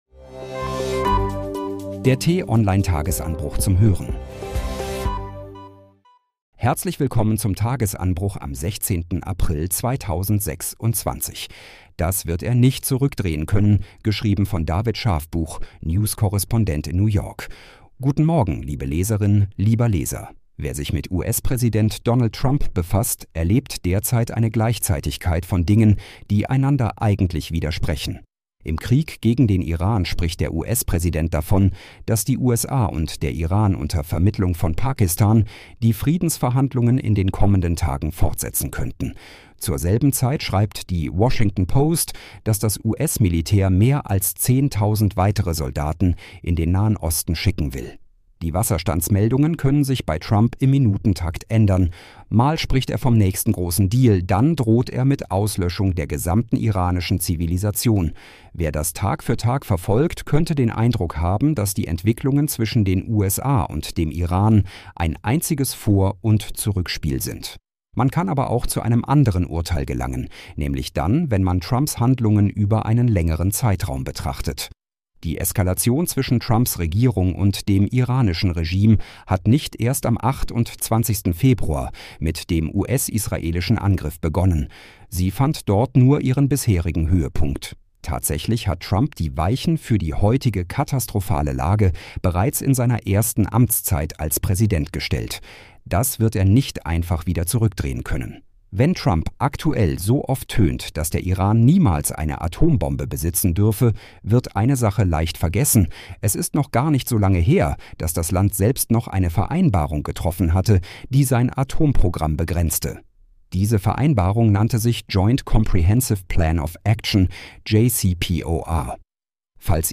zum Start in den Tag vorgelesen von einer freundlichen KI-Stimme –